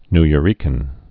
(nyə-rēkən)